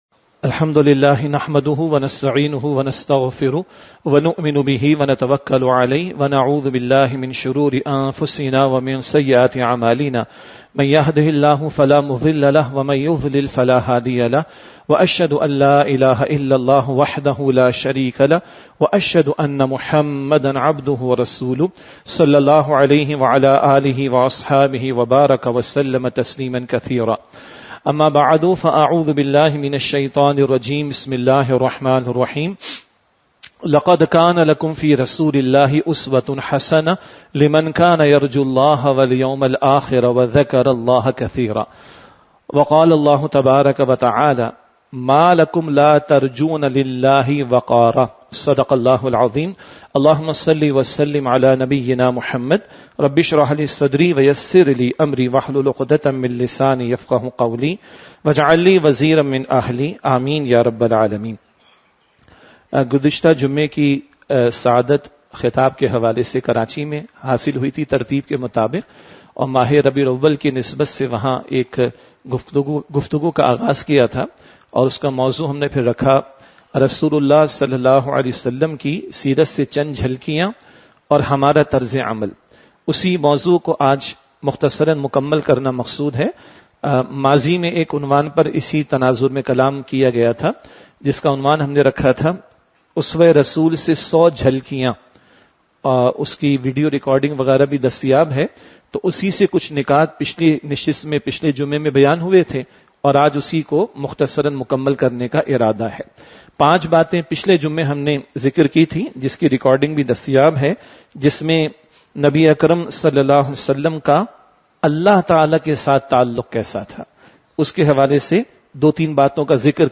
Masjid Jame Al-Quran, Quran Academy Lahore.
Khutbat-e-Jummah (Friday Sermons)